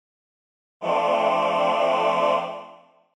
While still building chords using the Major Scale as building blocks, let's start by adding the sixth degree in the scale - so …  1   3   5   6
This is called a Six Chord; this one specifically is F6.
f6_chord.mp3